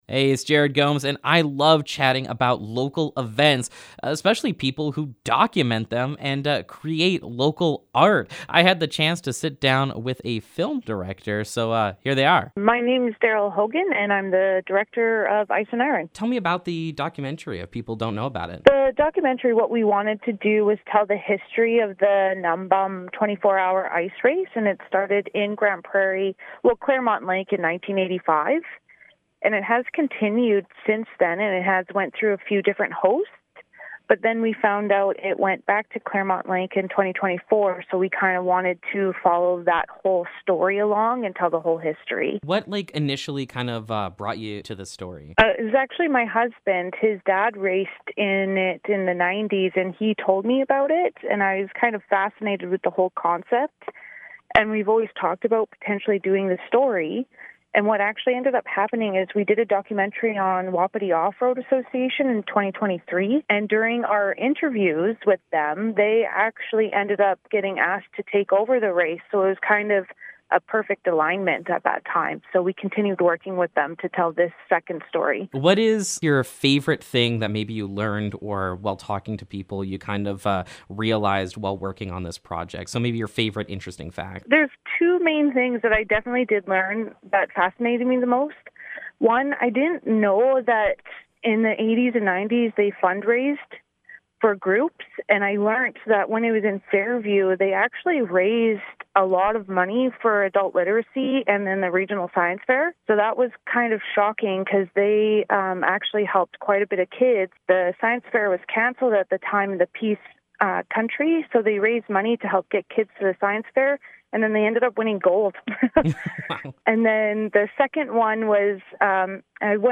Ice & Iron Interview
ice-and-iron-interview-final.mp3